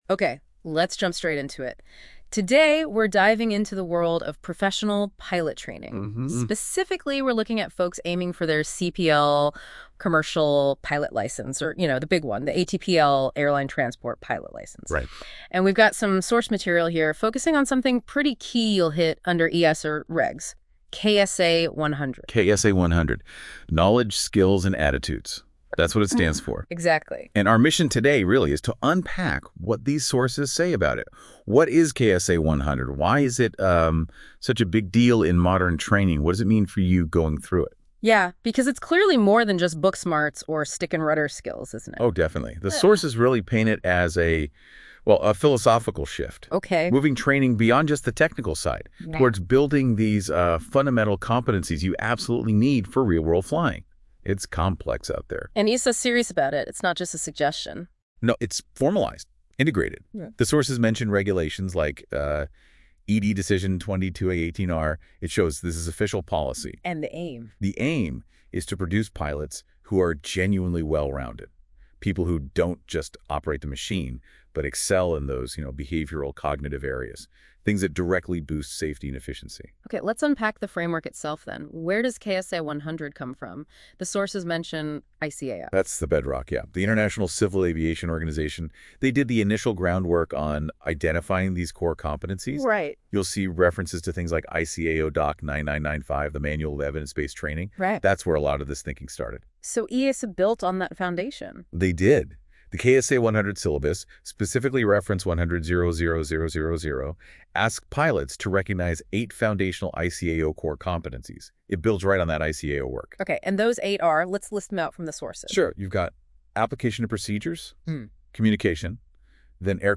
Listen to an audio discussion on this topic: Unpack the EASA KSA-100 requirements and understand the core competencies essential for modern pilots to ensure safety and professionalism.